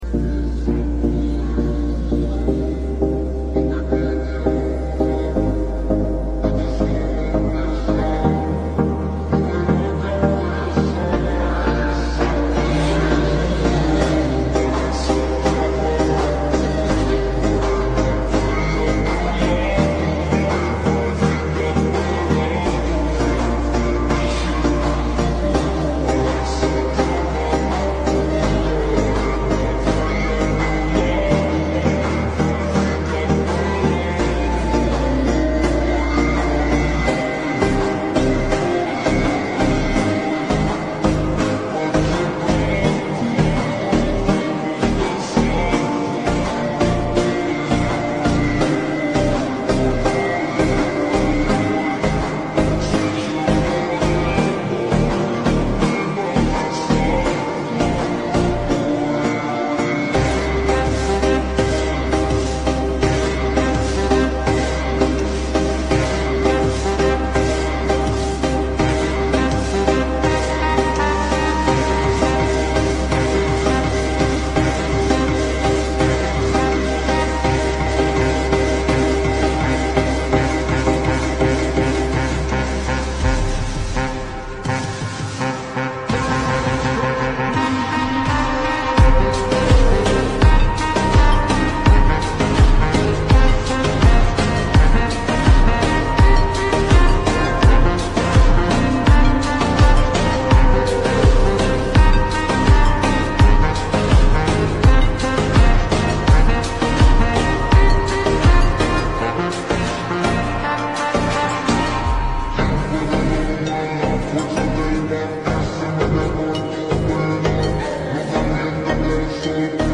Remix slowed reverb